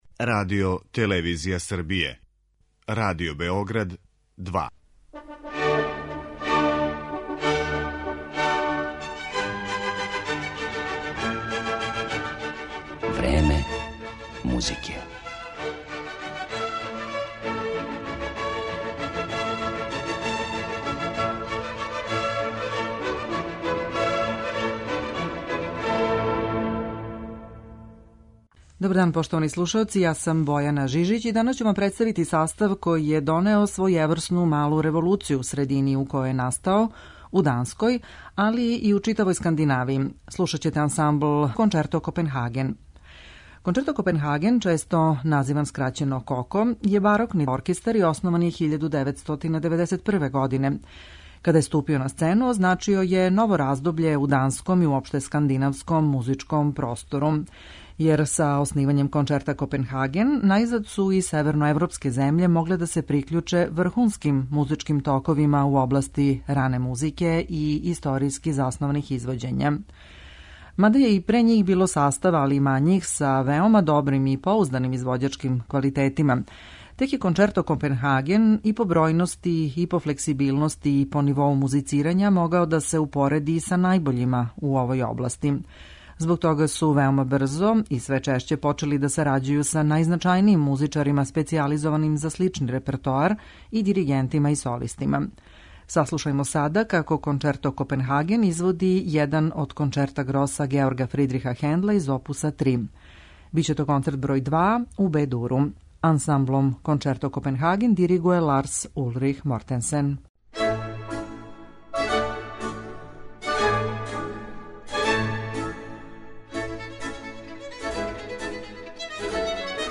У данашњој емисији представљамо ансамбл захваљујући коме се и Скандинавија прикључила врхунским музичким токовима у свету ране музике и историјски заснованих извођења. Слушаћете дански састав Concerto Copenhagen којим руководи једна од најистакнутијих личности у овој области, чембалиста и диригент Ларс Улрих Мротенсен. Ови музичари ће изводити дела барокних великана, али и имање познатих аутора који су обележили скандинавску историју музике.